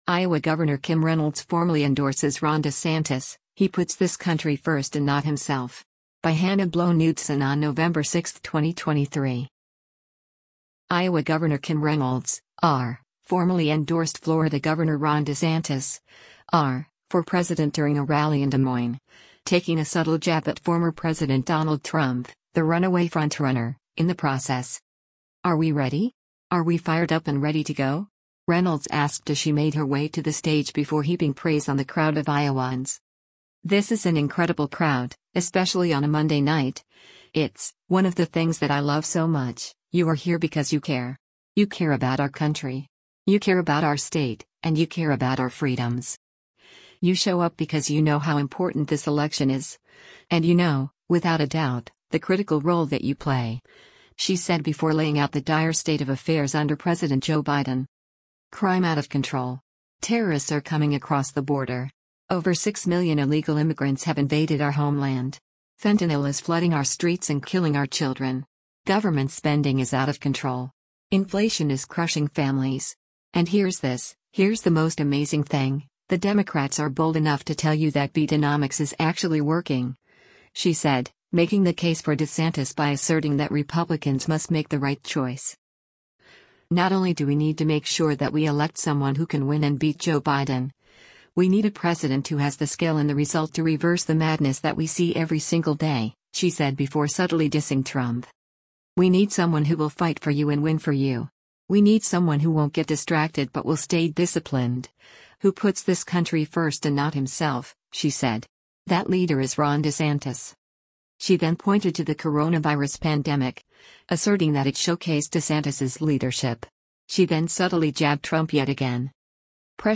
Iowa Gov. Kim Reynolds (R) formally endorsed Florida Gov. Ron DeSantis (R) for president during a rally in Des Moines, taking a subtle jab at former President Donald Trump — the runaway frontrunner — in the process.
“Are we ready? Are we fired up and ready to go?” Reynolds asked as she made her way to the stage before heaping praise on the crowd of Iowans.